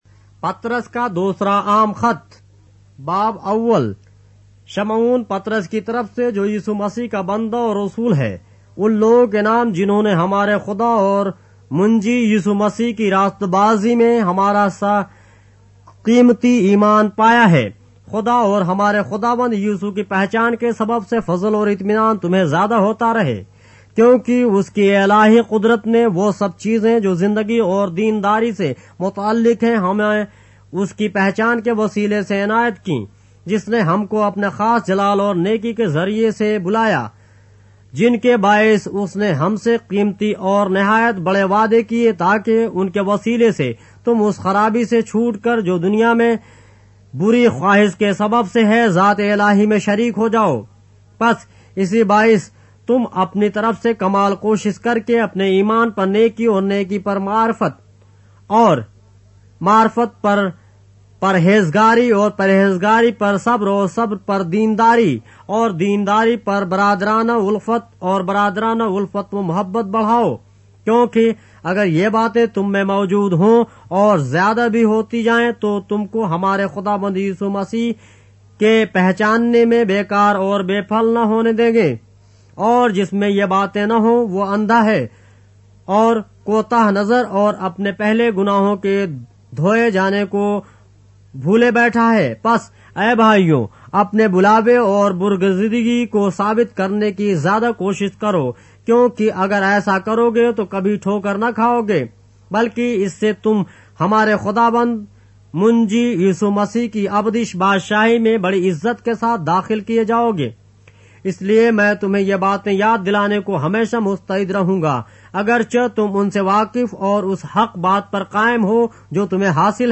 اردو بائبل کے باب - آڈیو روایت کے ساتھ - 2 Peter, chapter 1 of the Holy Bible in Urdu